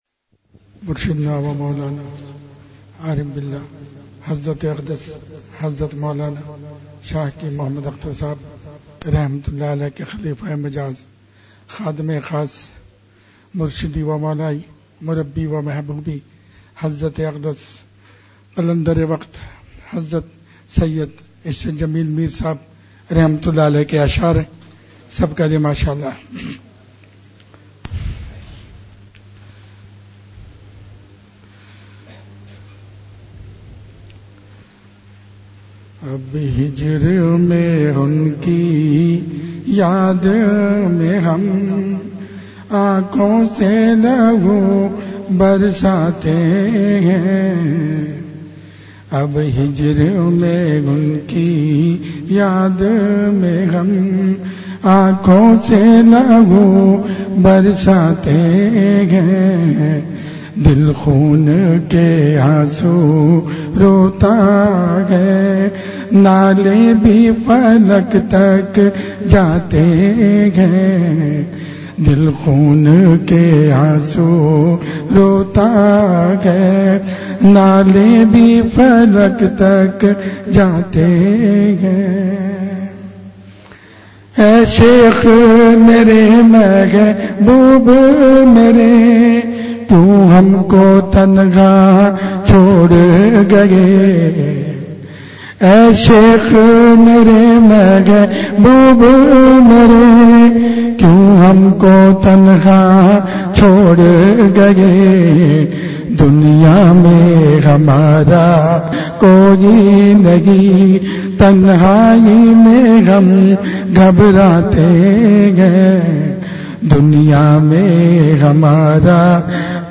مجلس ۲۸جون ۲۰۱۵ء :۱۹۹۴ء میں حضرت والاؒ کی بہت خاص مجلسِ اشعار
حضرت والا نے کرم فرمایا اوردرد بھرےاشعار ترنم سے سنائے۔